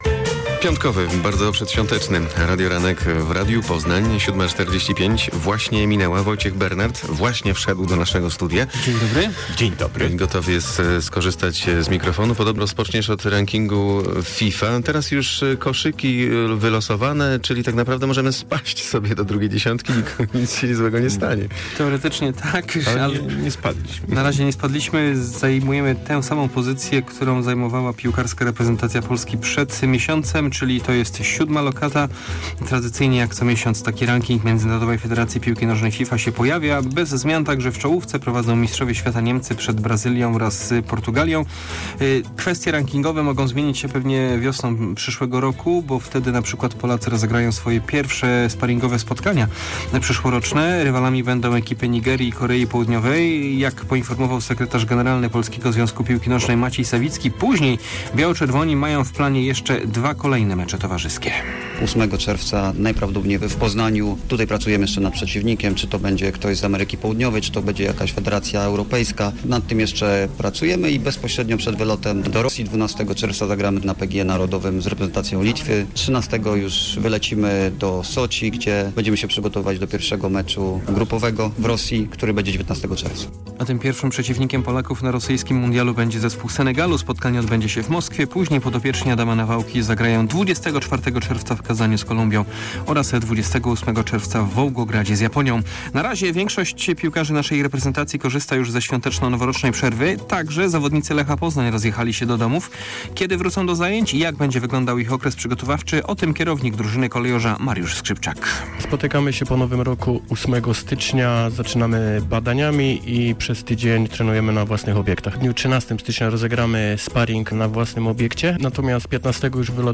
22.12 serwis sportowy godz. 7:45